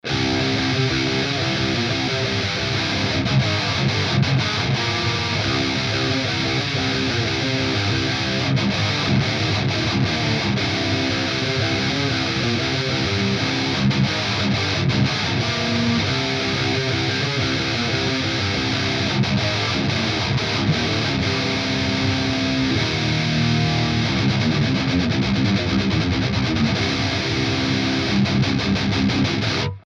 Wenn ich den Amp beschreiben müsste würde ich sagen er hat die Brutalität und ist charakterlich verwand mit nem 5150, dabei aber irgendwie "saftiger" und "wärmer".
hier ist mal ein kleiner Clip...immer gleich Regler-Einstellung,m nur unterschiedliche Mics:
sm57
natürlich NULL Nachbearbeitung, also auch kein HP/LP oder irgendwatt.